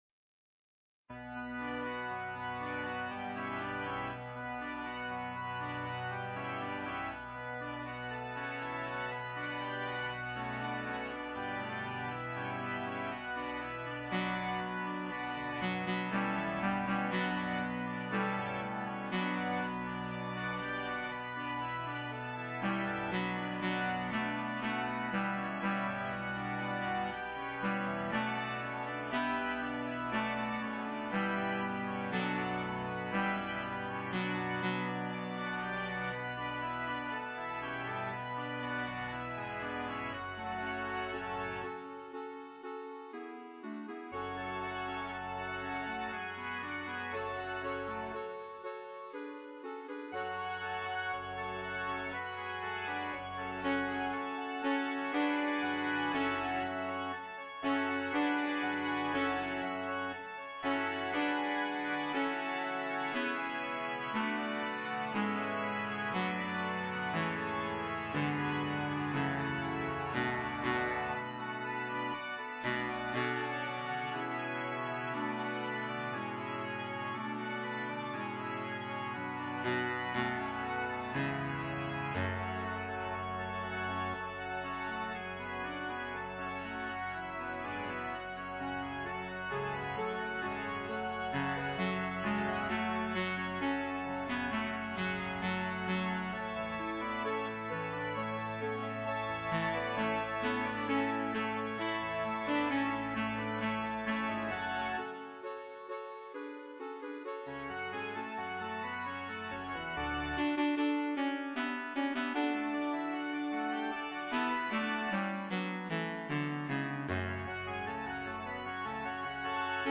Remembrance Sunday Anthem MP3s Sop Alto Tenor Bass
bassthey-that-go-down-to-the-seab.mp3